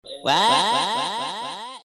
What(Echos)